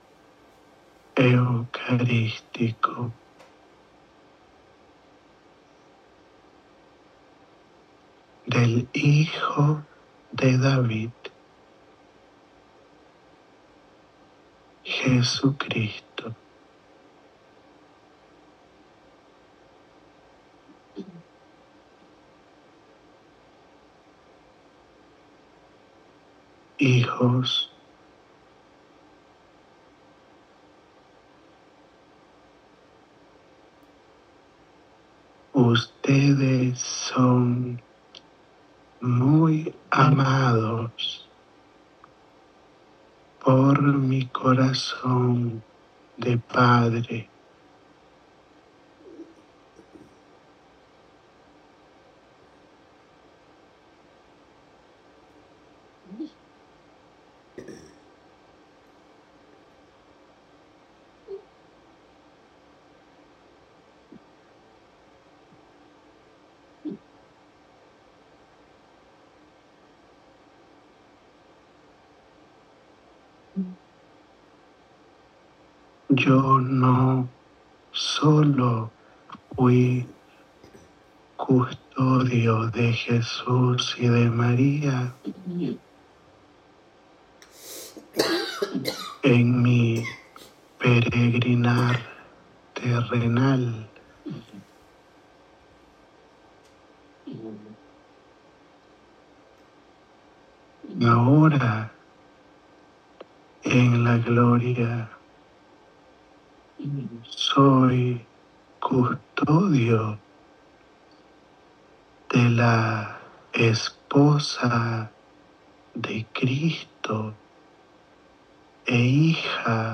Audio da Mensagem
Durante a Jornada Josefina de 2026